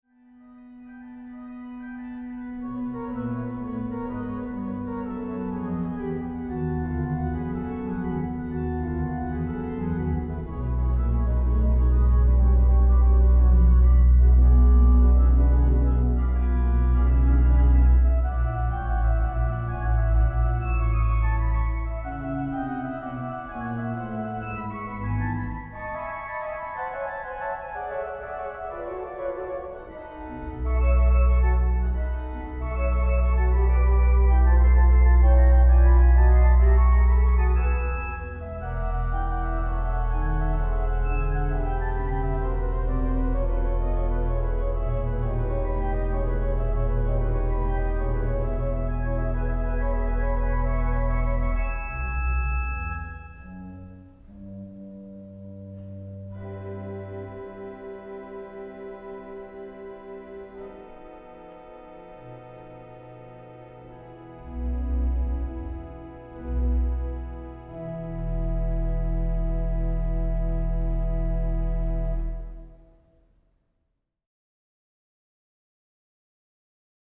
Organ duo
A softly bouncing scherzo is a pleasing contrast.